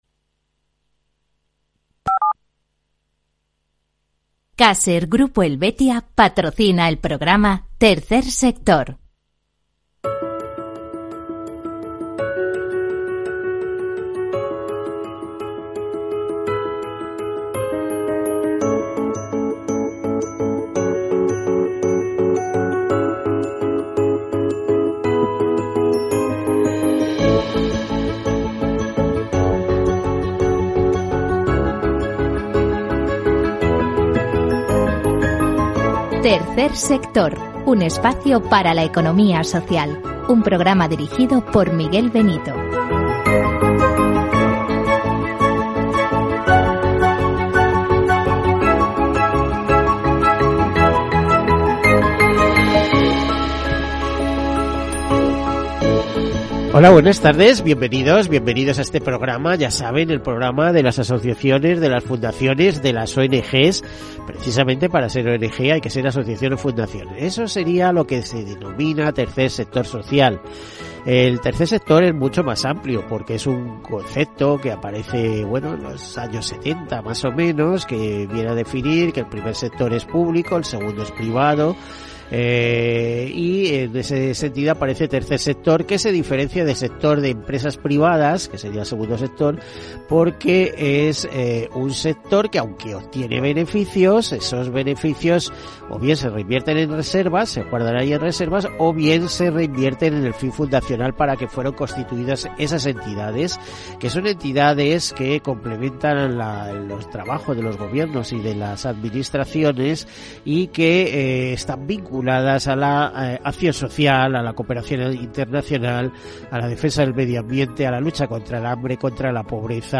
El programa dedicado a la economía solidaria y social. Información y entrevistas sobre fundaciones, asociaciones, ONG, cooperativas, mutuas, mutualidades, iniciativas de RSC, etcétera, que actúan con ausencia de lucro ante pequeños y grandes temas de marcado interés general.